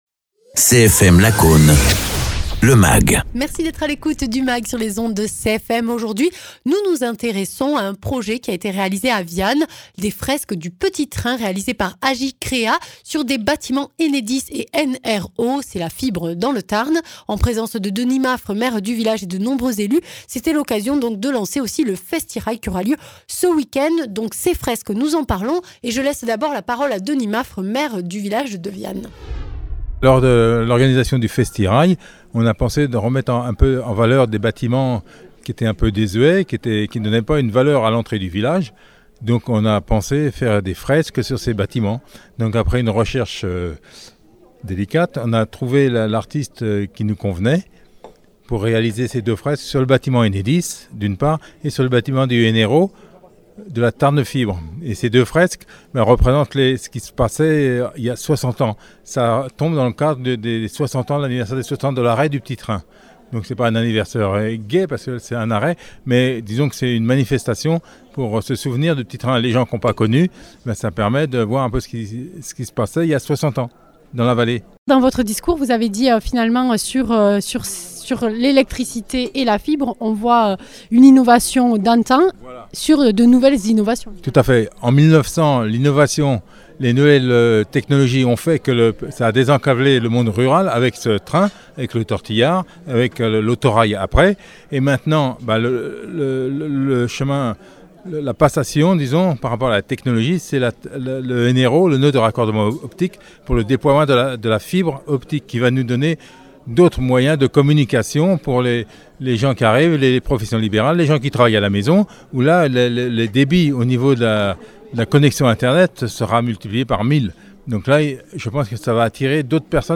Interviews
Invité(s) : Denis Maffre, maire de Viane-Pierre-Ségade (Tarn)